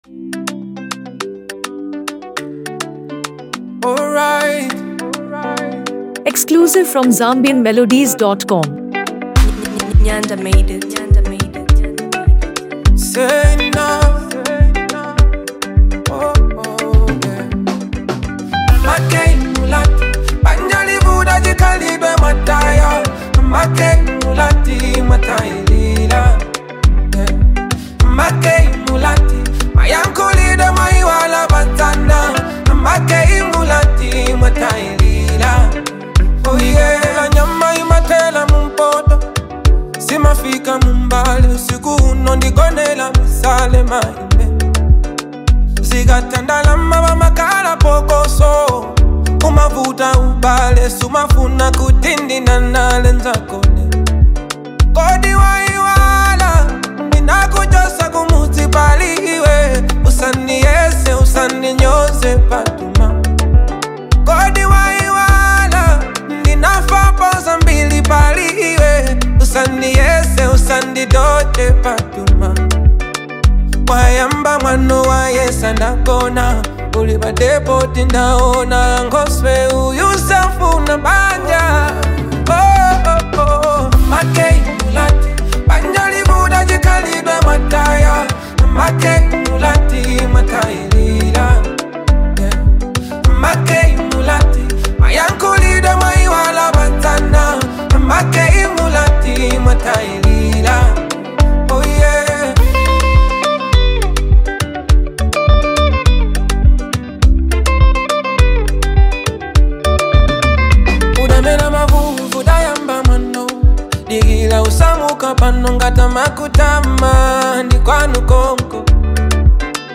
soulful and infectious